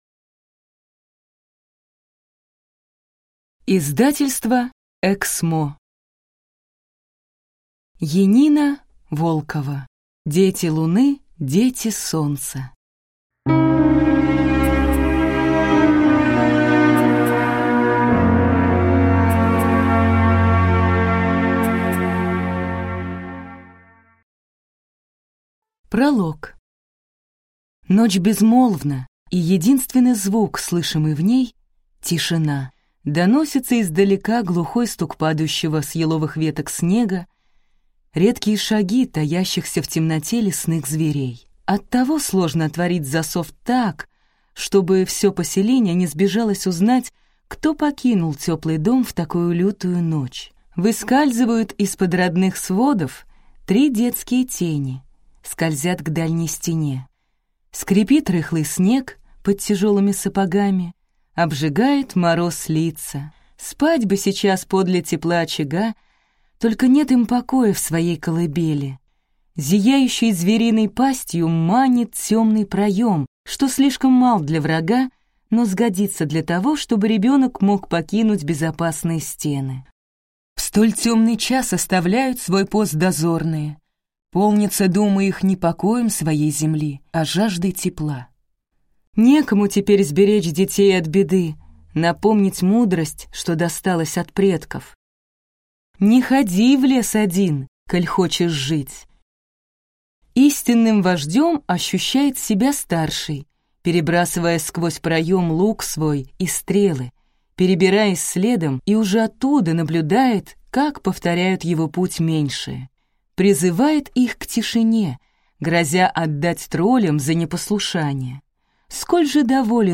Аудиокнига Дети луны, дети солнца | Библиотека аудиокниг